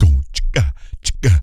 DEEP LATIN.wav